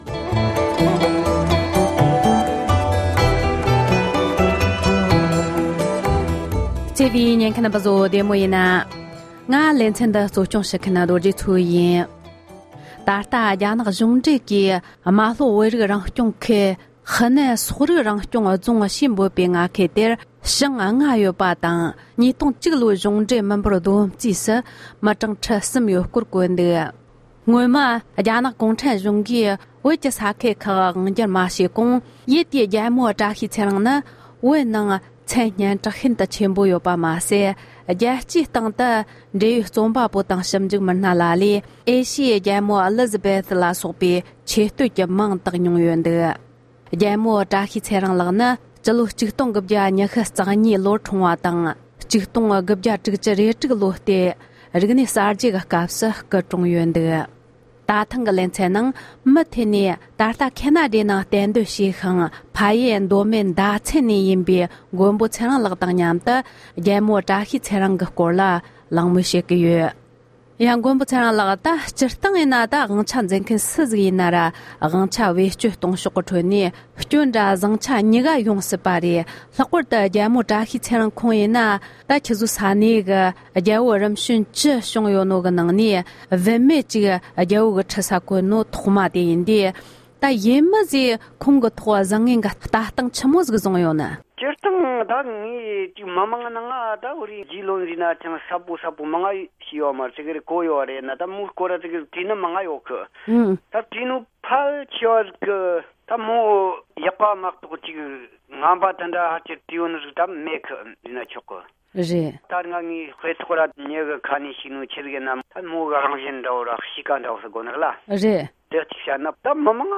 གླེང་མོལ་བྱས་པར་གསན་རོགས་གནོངས༎